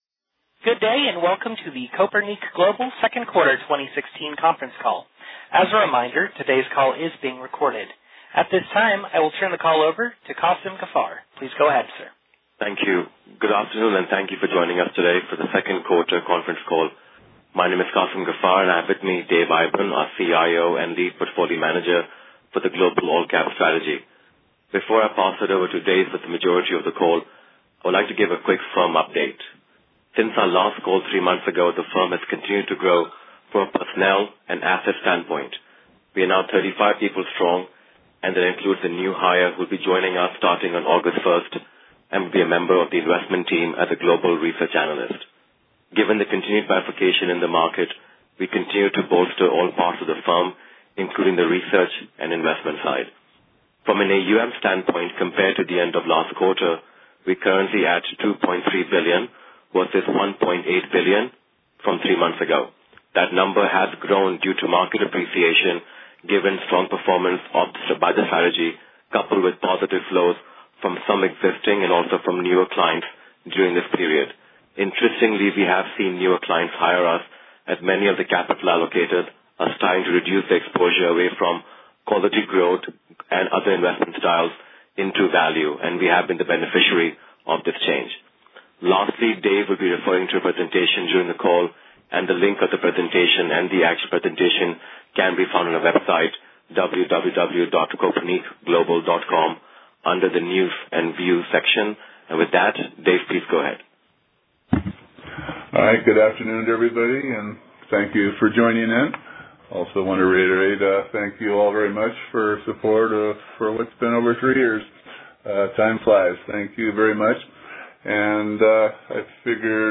Q2 2016 Conference Call - Kopernik Global Investors
Kopernik-2Q-2016-Conference-Call-Audio-Final.mp3